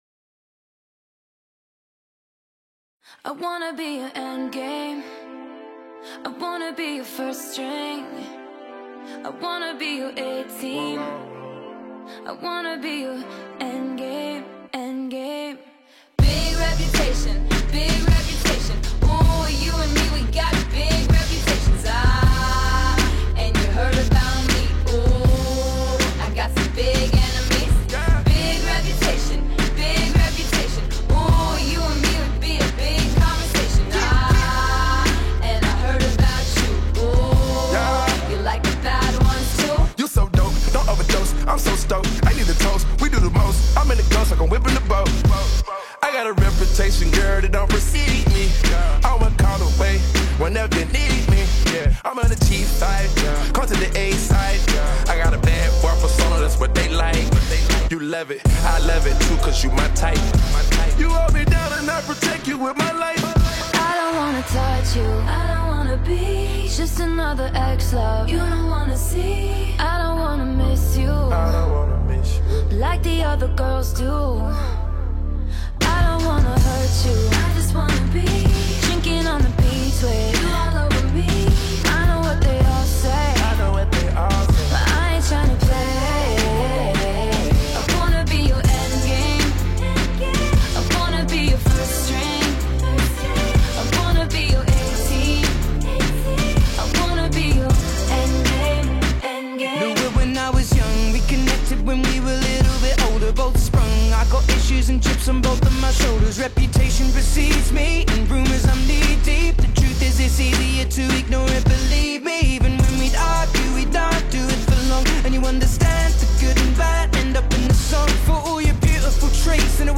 American female singer
the raps